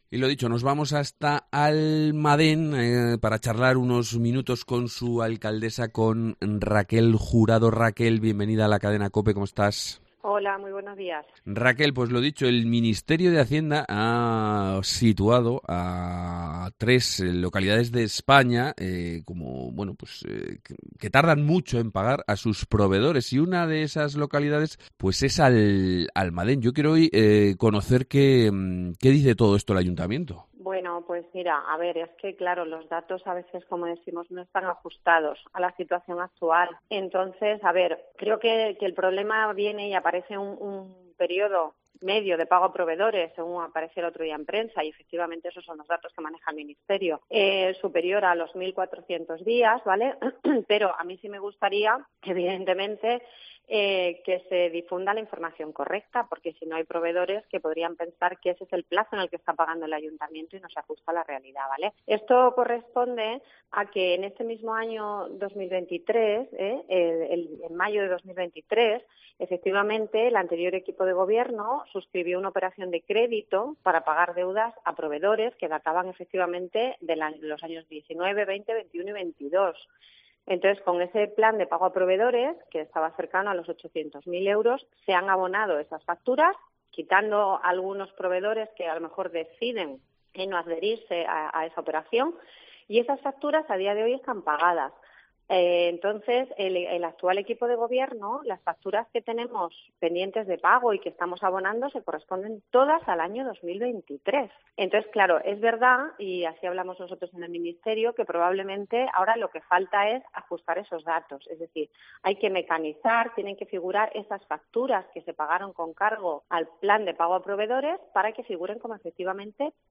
Raquel Jurado, alcaldesa de Almadén
Entrevista